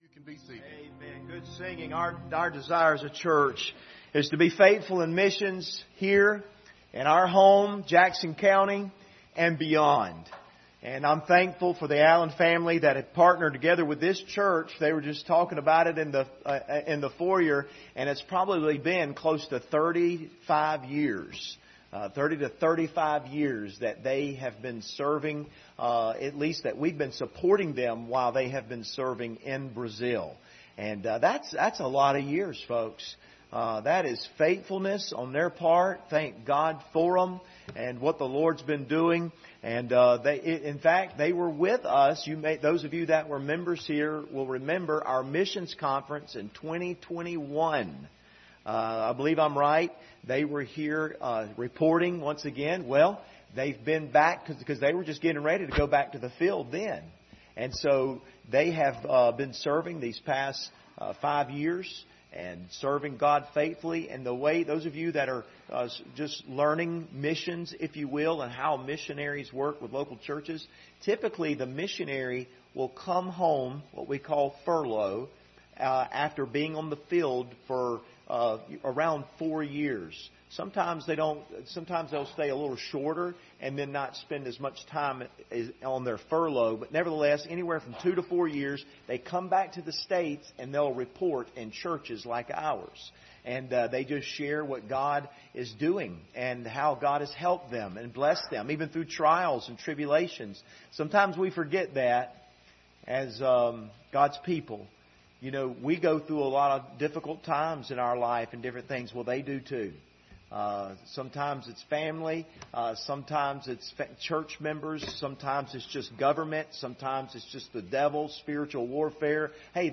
Passage: Romans 10:13-15 Service Type: Sunday Evening